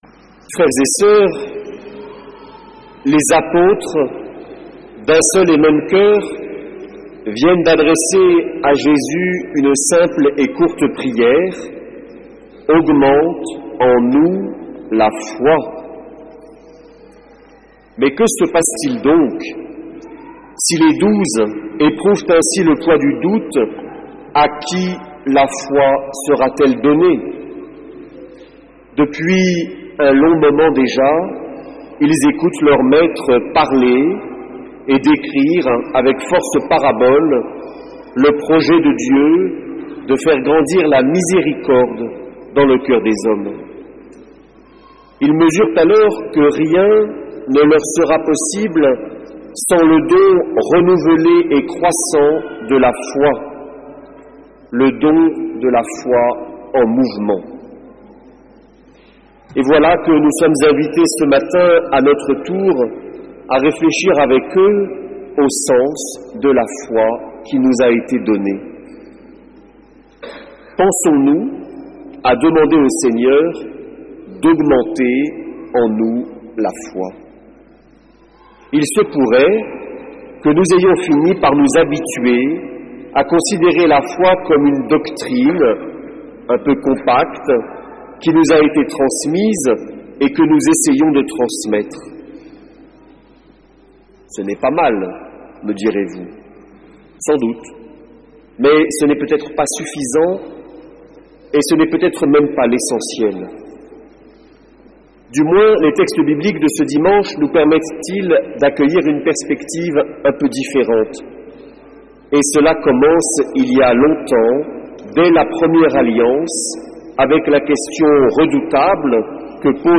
homélies